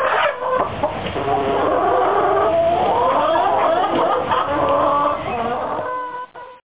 hens.mp3